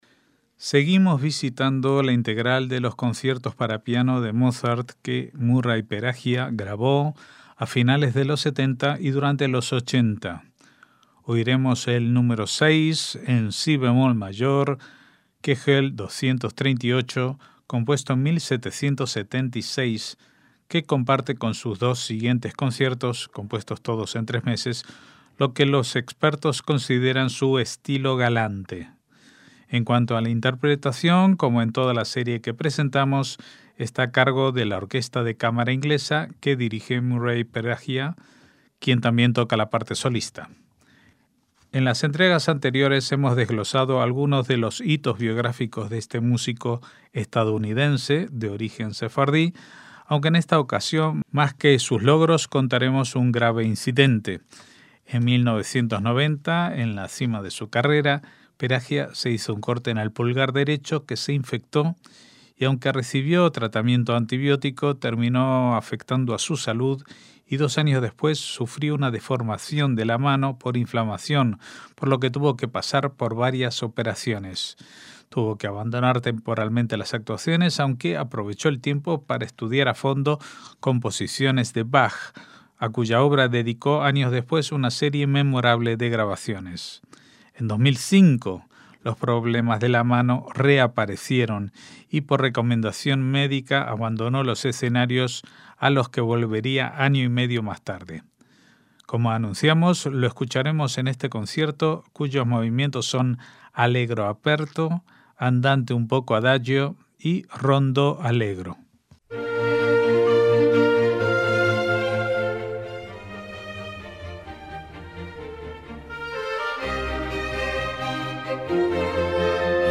MÚSICA CLÁSICA
en si bemol mayor